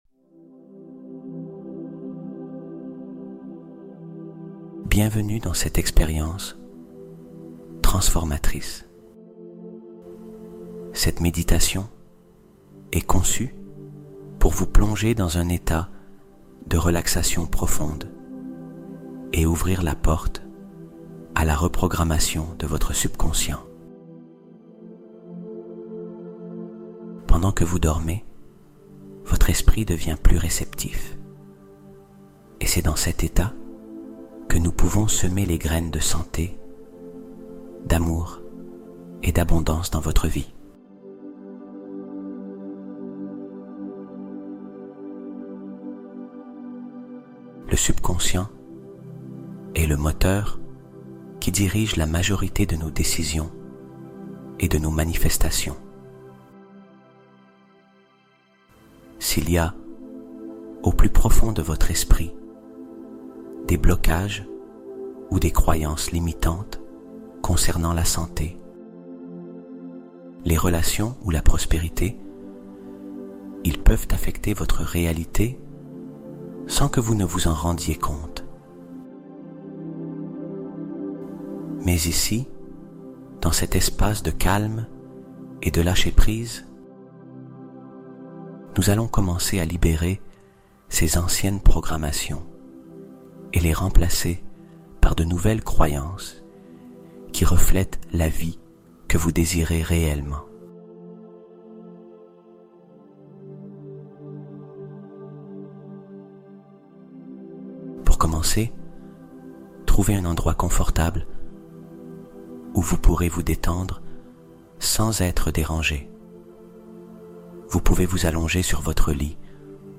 Attire Santé, Amour et Argent Pendant Ton Sommeil : Hypnose Guidée Complète (Résultats Garantis)